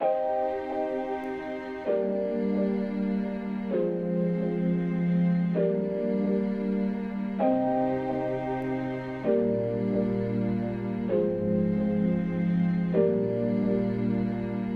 Tag: 130 bpm Trap Loops Piano Loops 2.48 MB wav Key : C Ableton Live